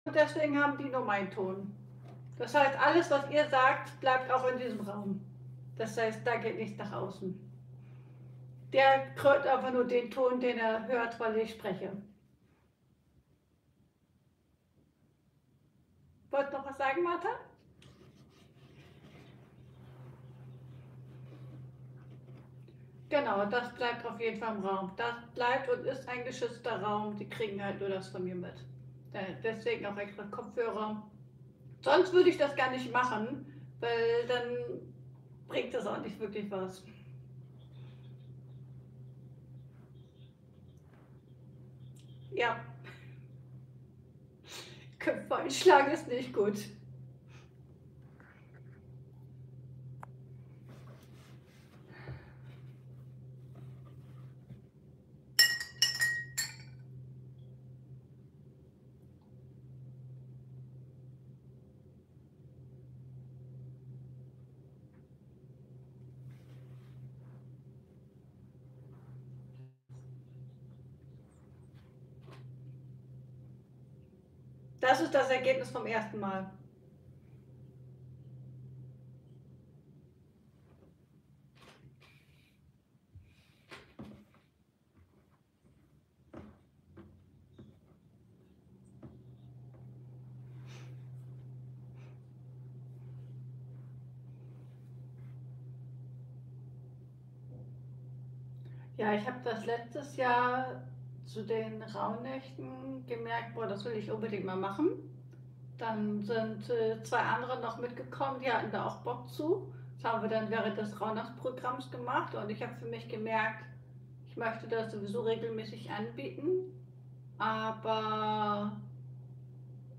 Livemitschnitt das goldene Herz ~ Ankommen lassen Podcast